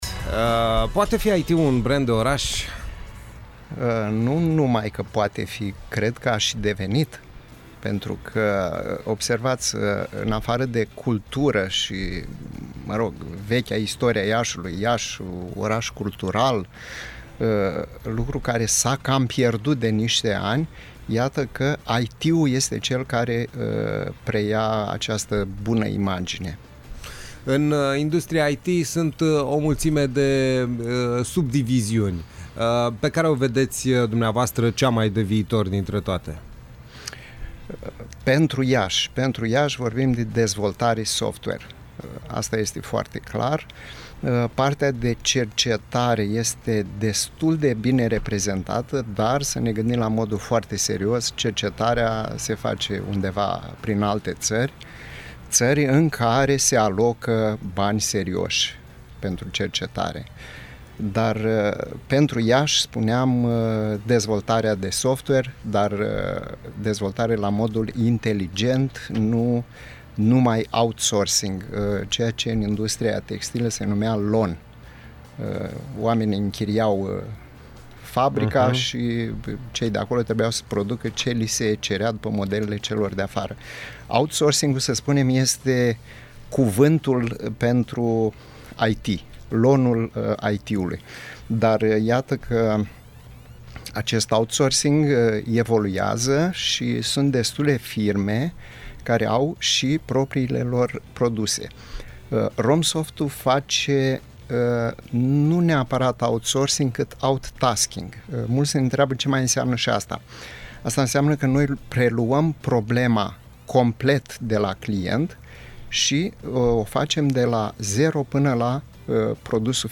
INTERVIU.